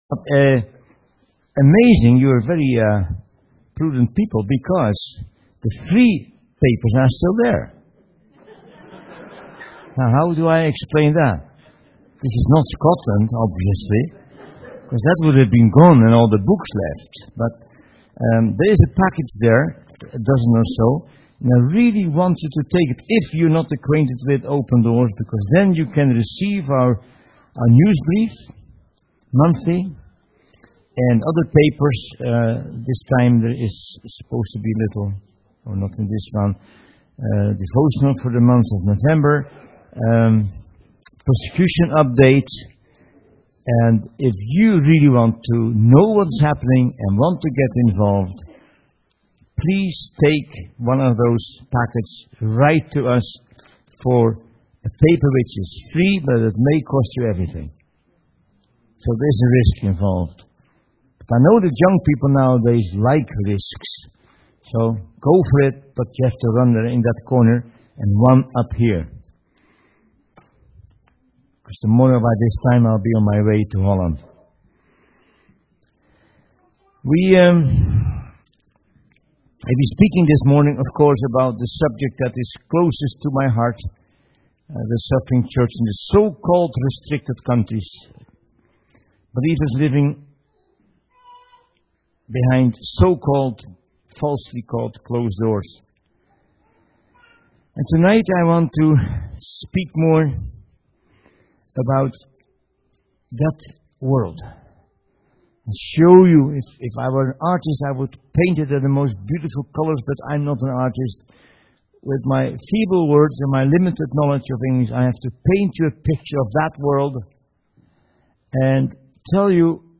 In this sermon, the speaker shares a powerful encounter with a street child who wandered into their church service.